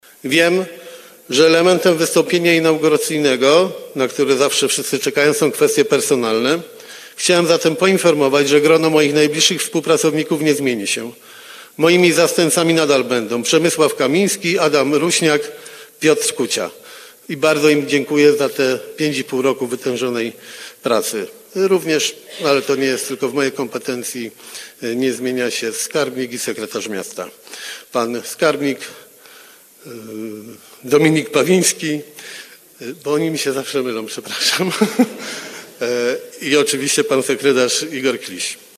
W trakcie wystąpienia inauguracyjnego prezydent Jarosław Klimaszewski poinformował, że grono jego najbliższych współpracowników się nie zmieni.
Słowa te padły podczas dzisiejszej, pierwszej sesji Rady Miejskiej w Bielsku-Białej kadencji 2024-2029.